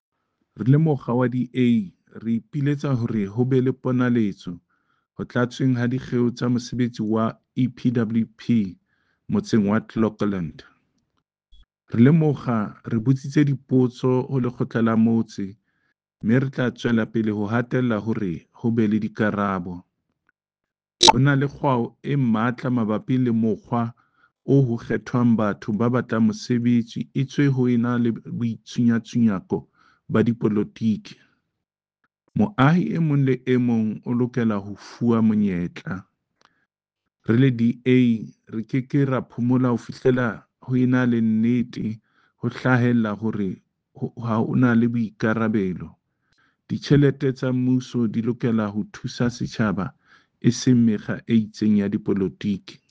Sesotho soundbite by Cllr David Masoeu MPL.